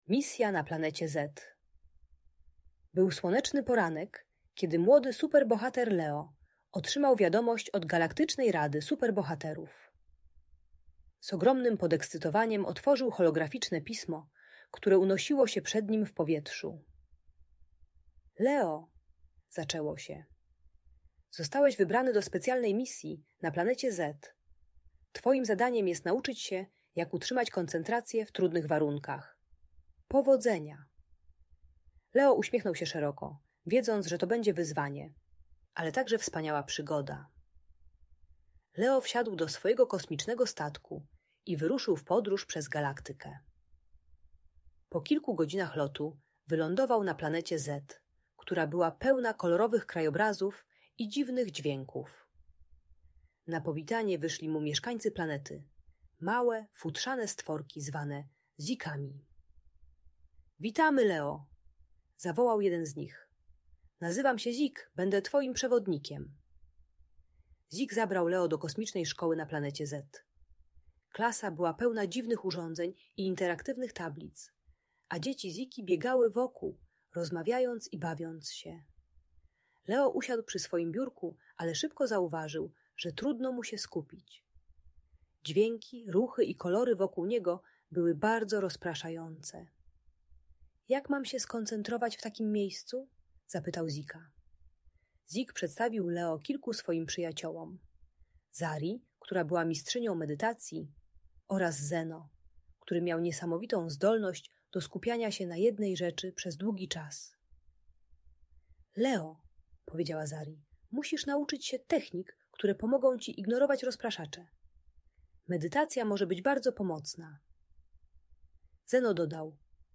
Misja na Planecie Z - Szkoła | Audiobajka